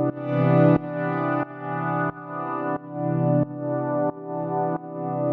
GnS_Pad-dbx1:4_90-C.wav